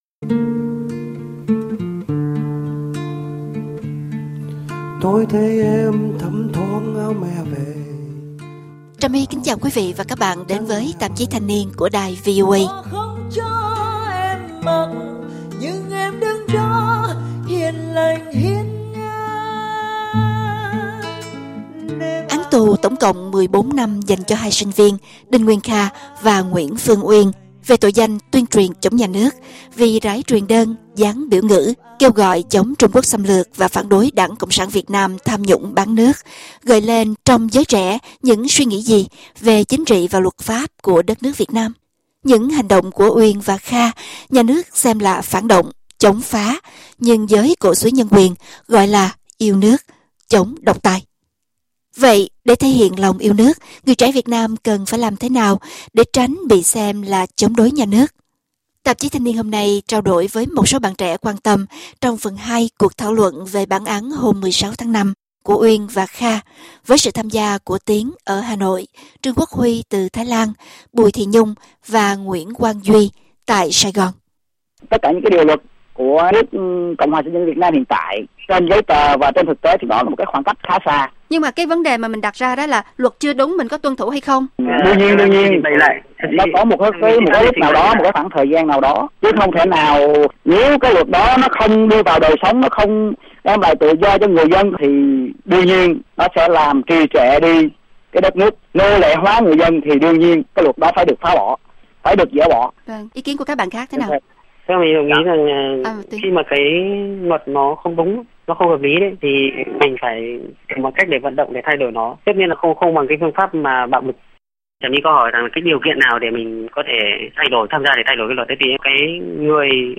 Một số bạn trẻ từ 2 miền đất nước chia sẻ ý kiến trong phần 2 cuộc thảo luận về bản án hôm 16